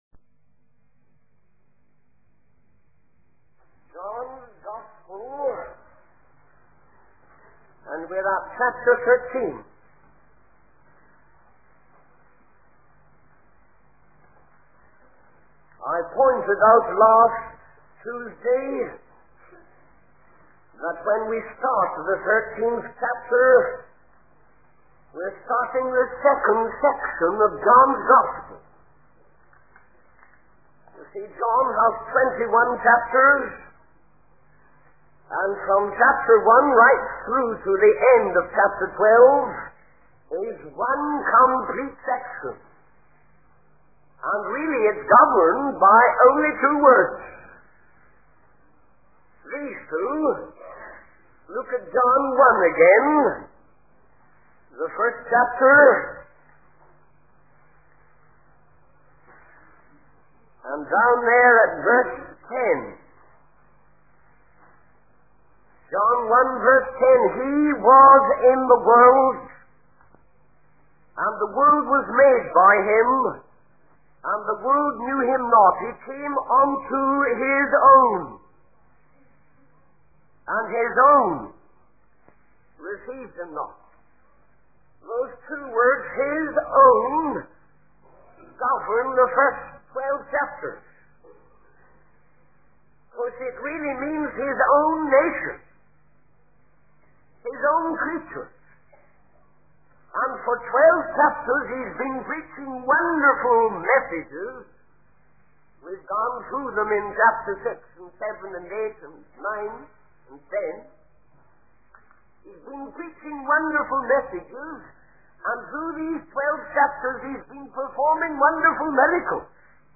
In this sermon, the preacher focuses on the story of Jesus washing the disciples' feet as a demonstration of humility and servanthood. The preacher emphasizes the significance of Jesus removing his garment and taking on the appearance of a slave to wash the disciples' feet.